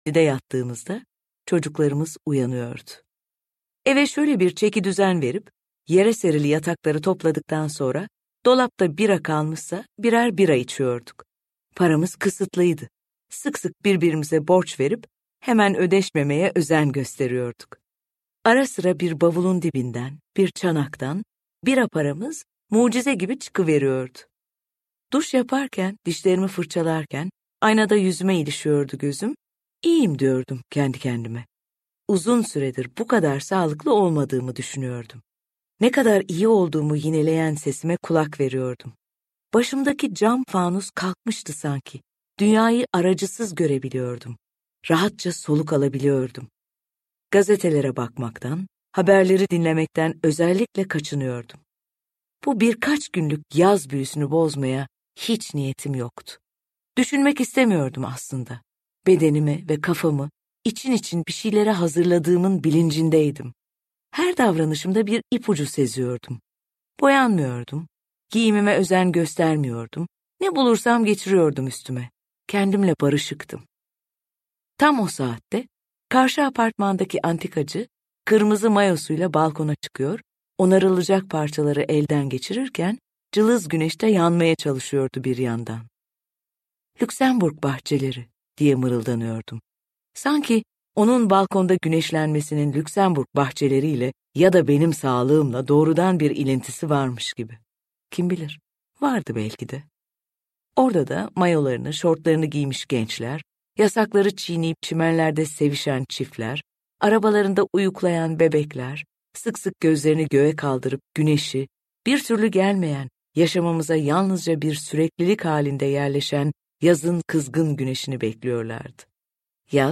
Yaza Yolculuk’ta öğretilen basmakalıp değer yargılarının yeniden gözden geçirilmesi, kişinin kendine ve yaşadığı yere dönüş yolculukları yaz mevsiminin aracılığıyla anlatılıyor. 1987 Sait Faik Öykü Ödülü’nü kazanan kitap, yazarın iç yolculuğunun, iç hesaplaşmalarının en güzel örneklerinden biri olma özelliğini de taşıyor. Yaza Yolculuk’u Tilbe Saran’ın seslendirmesiyle dinleyebilirsiniz.